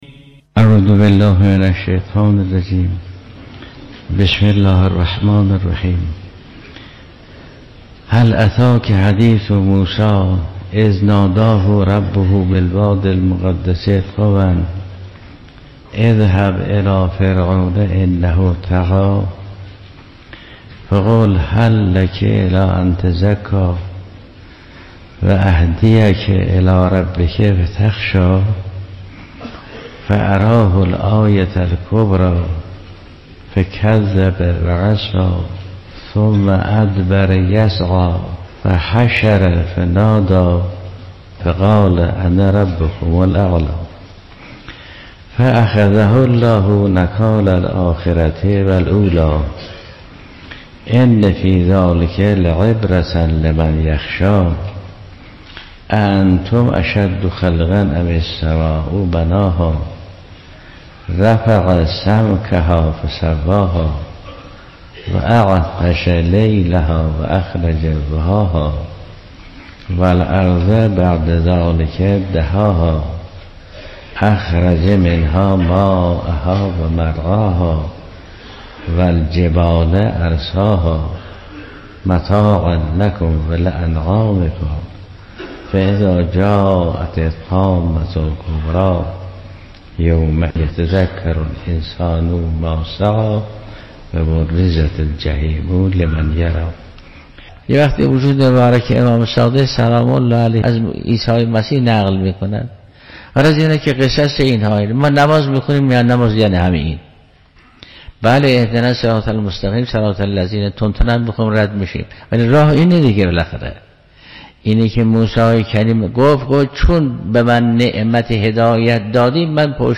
برش‌هایی از سخنرانی و تفسیر مفسر و عارف گرانقدر آیت‌الله جوادی‌آملی در برنامه رادیویی سروش هدایت ارائه می‌شود.